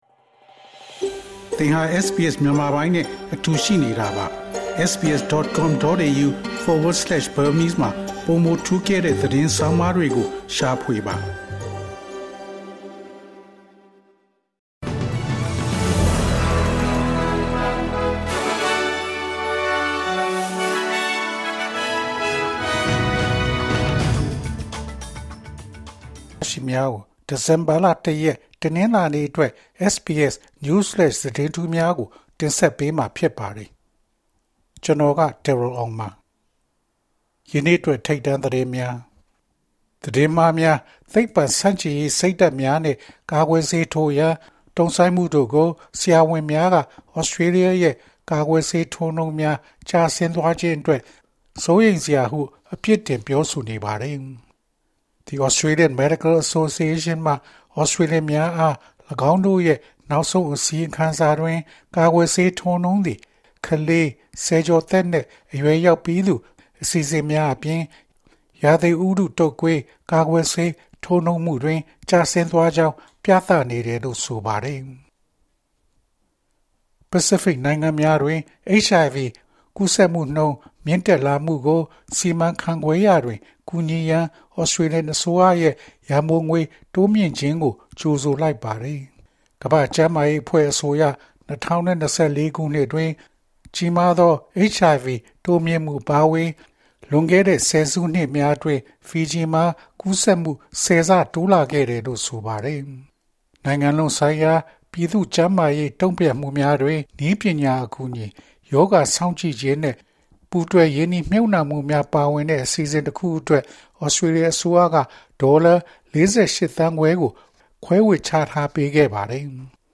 ၂၀၂၅ ဒီဇင်ဘာလ ၁ ရက် တနင်္လာနေ့ အတွက် SBS Newsflash သတင်းထူးများ။